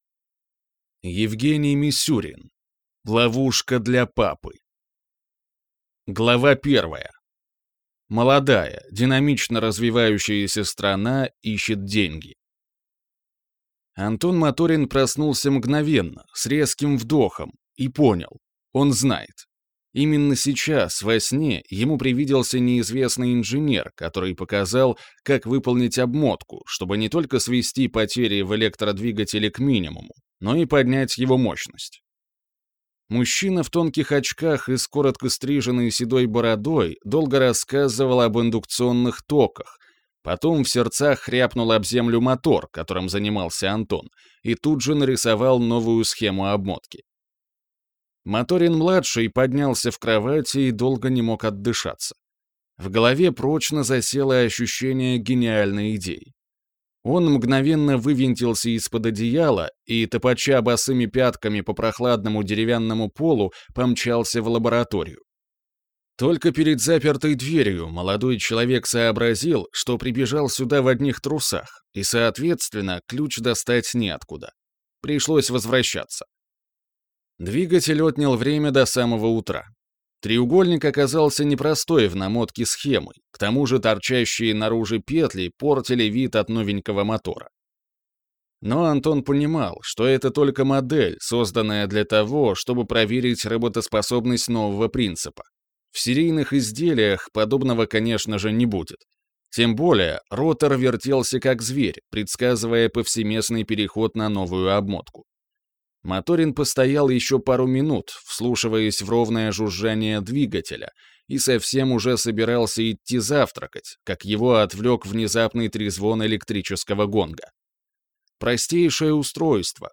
Аудиокнига Ловушка для папы | Библиотека аудиокниг